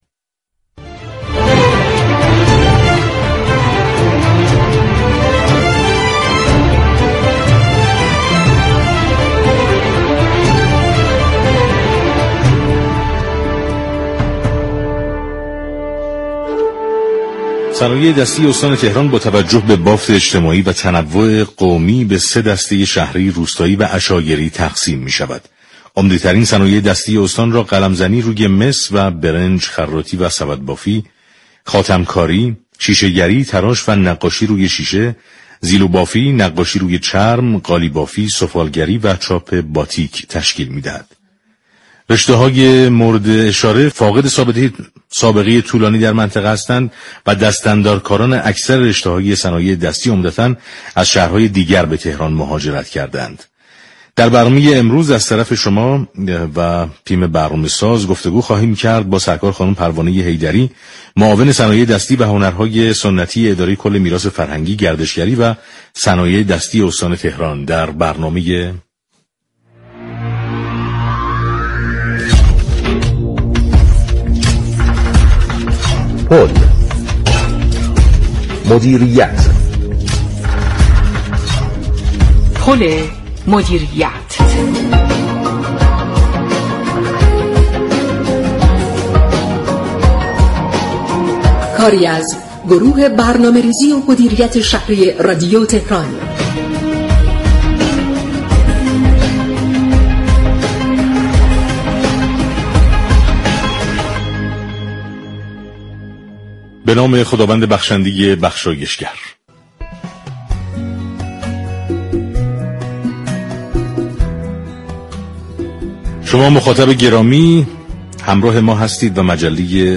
در گفت و گو با برنامه پل مدیریت